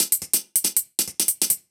Index of /musicradar/ultimate-hihat-samples/140bpm
UHH_ElectroHatD_140-04.wav